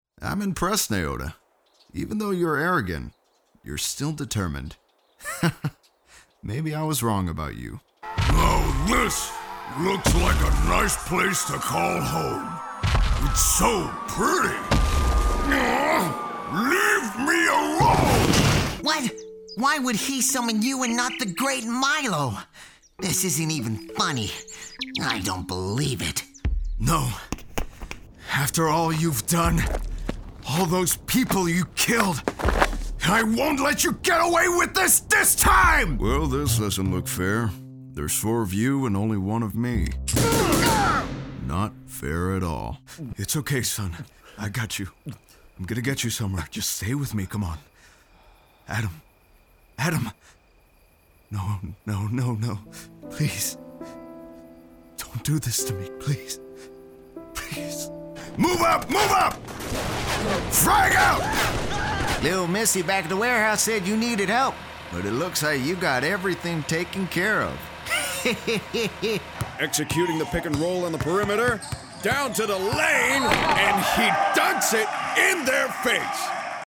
Male
Character / Cartoon
This Character Demo Demonstrates Vocal Range And Performance Skill. From Fathers To Monsters, And Forest Faeries To Cowboys, This Versatility Can Be Used For Cartoons, Animations, And Video Games!
Words that describe my voice are Warm, Deep, Natural.